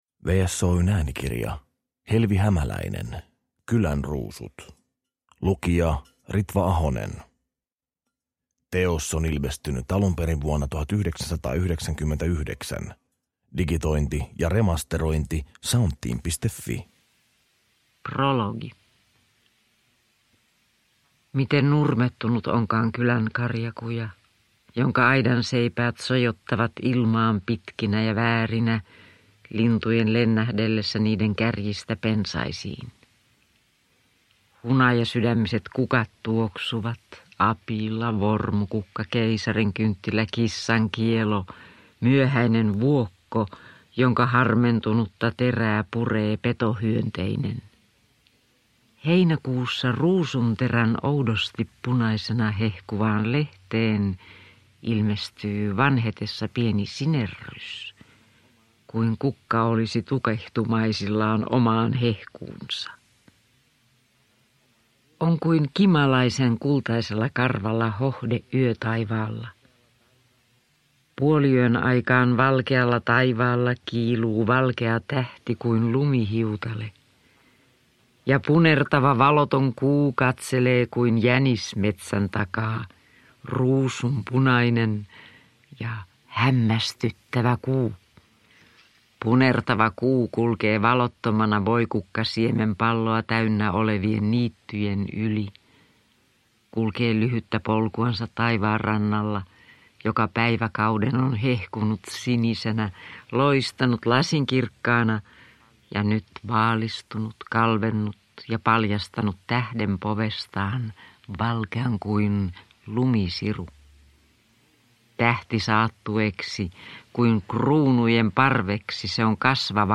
Kylänruusut – Ljudbok – Laddas ner